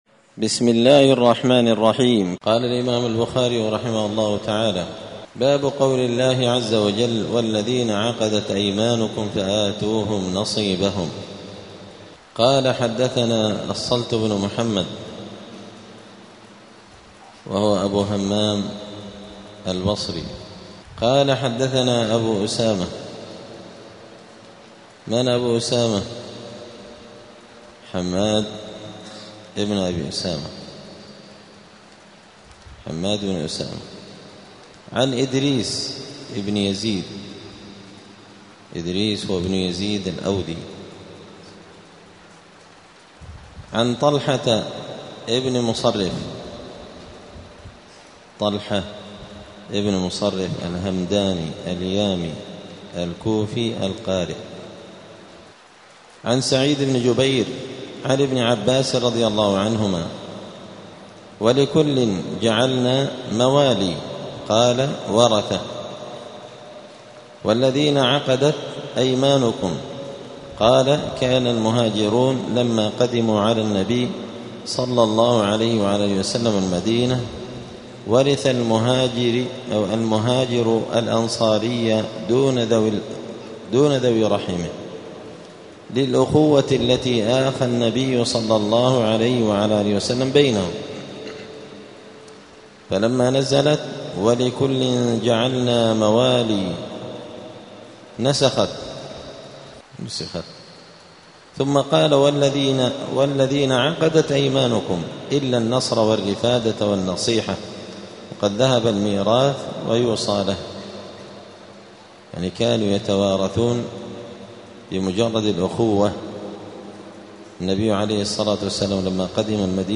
دار الحديث السلفية بمسجد الفرقان قشن المهرة اليمن
الأثنين 24 صفر 1447 هــــ | الدروس، دروس الحديث وعلومه، شرح صحيح البخاري، كتاب الكفالة من صحيح البخاري | شارك بتعليقك | 10 المشاهدات